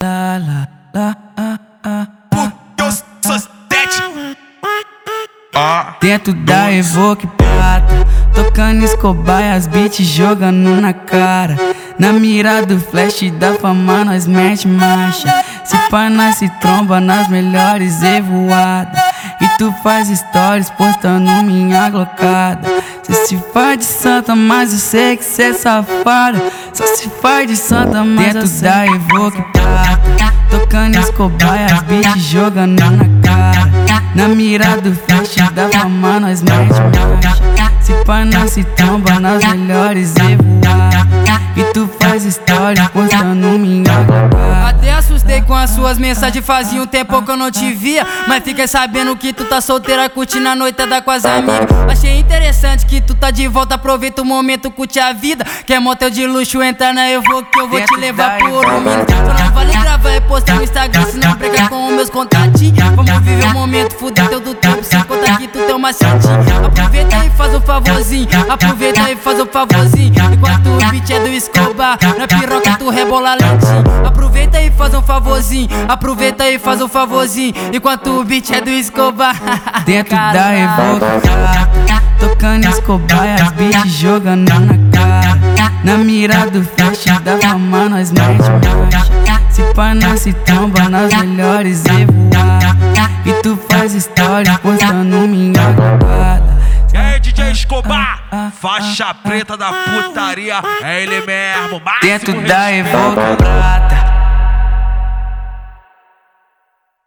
2025-02-23 14:13:25 Gênero: Funk Views